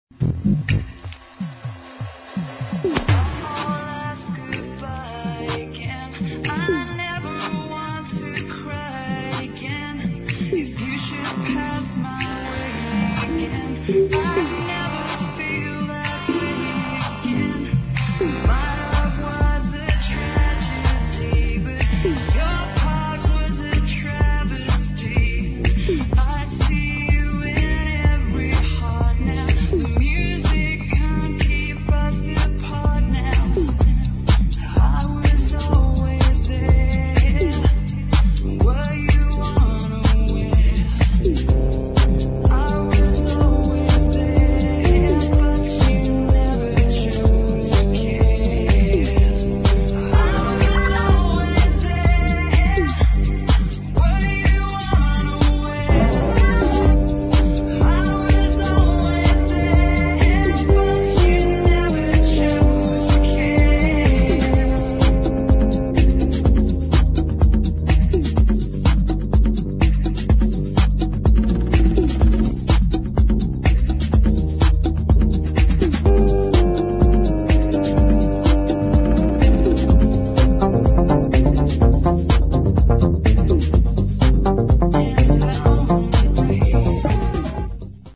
[ TECH HOUSE | DUB DISCO ]